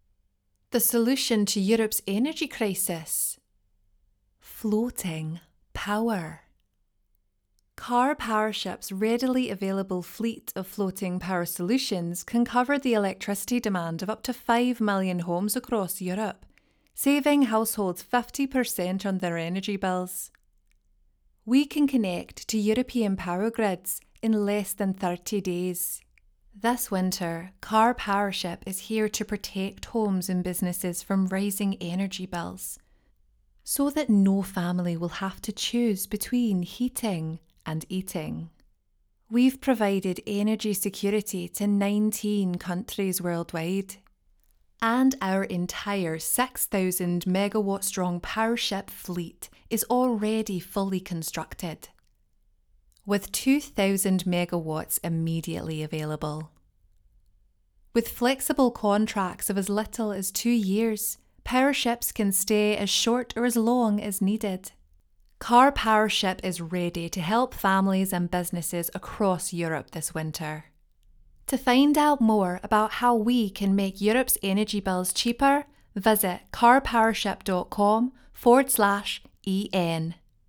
Cheerful, Neutral, Engaging, Current, Velvety, Expressive